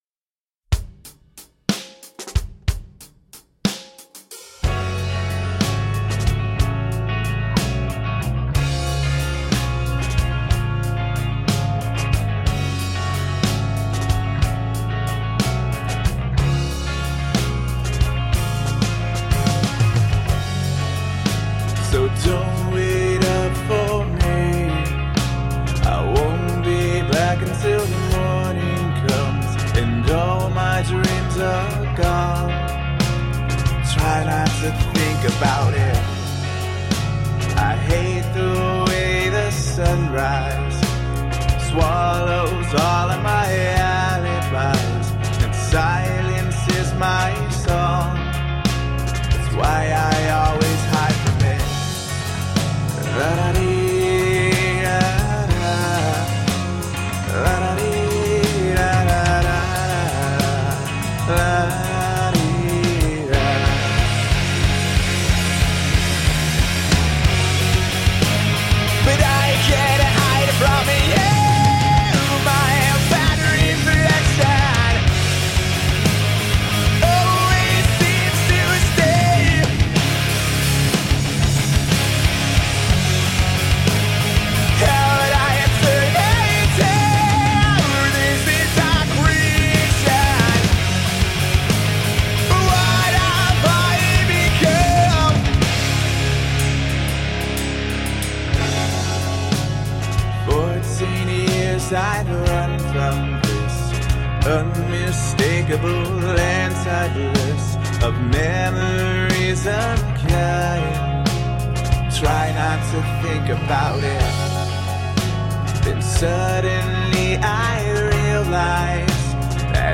Tagged as: Hard Rock, Rock, Intense Metal